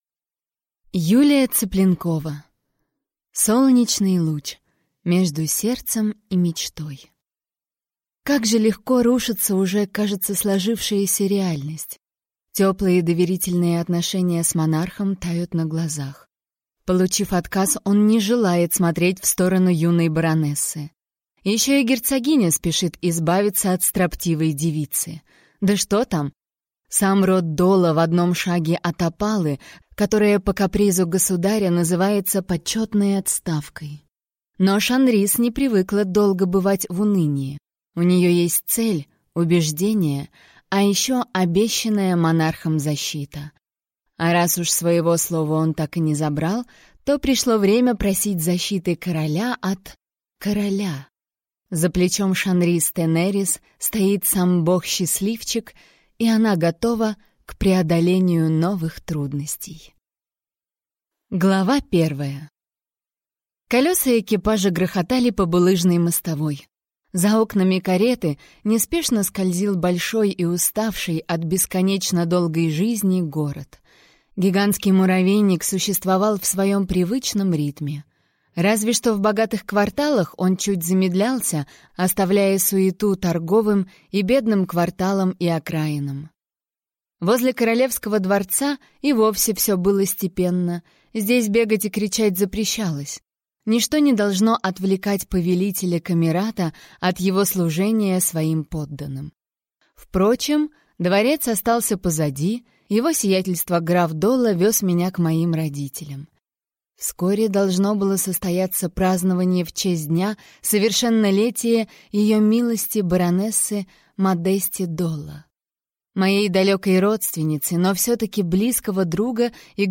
Аудиокнига Солнечный луч. Между сердцем и мечтой | Библиотека аудиокниг
Прослушать и бесплатно скачать фрагмент аудиокниги